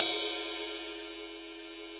CM2 RIDE   3.wav